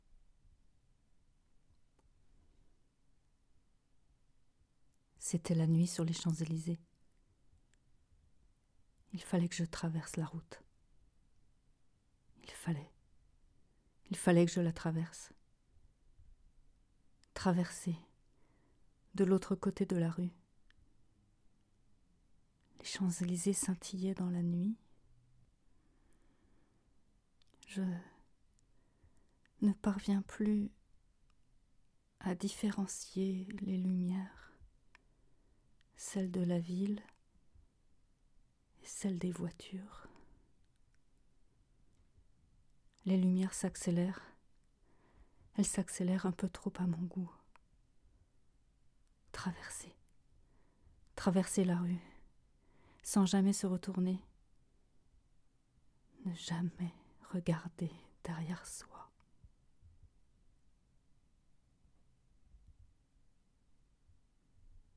40 - 55 ans - Mezzo-soprano